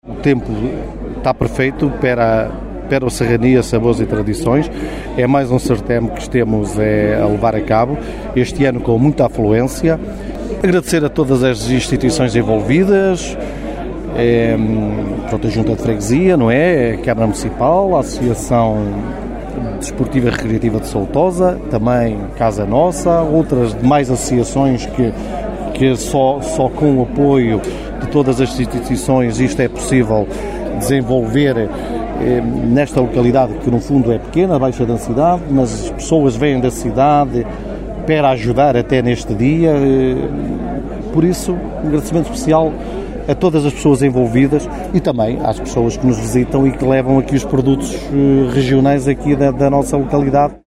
Pedro Sousa, Presidente da União de Freguesias de Peva e Segões, aproveitou a ocasião para agradecer a quem ajudou na organização deste evento, não esquecendo os visitantes, que, oriundos de vários pontos da região e do país, marcaram presença no Serranias, edição 2024.